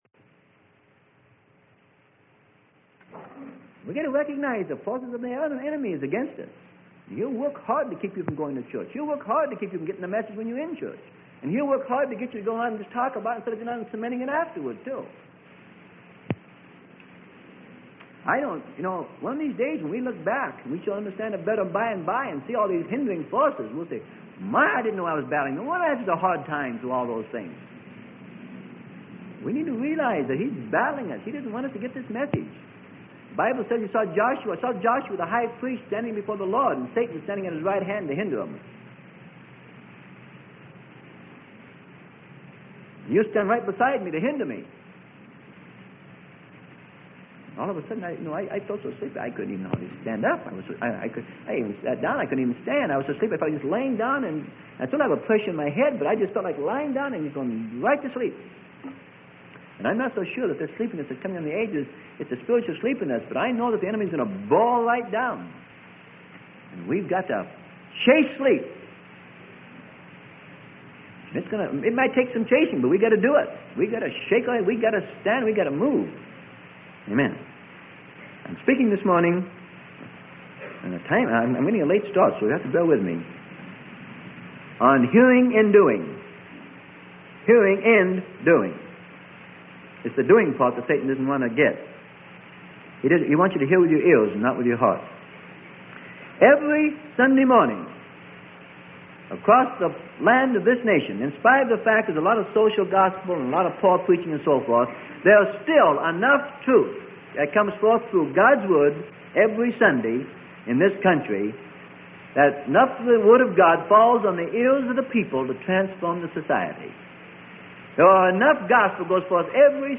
Sermon: Hearing and Doing - Freely Given Online Library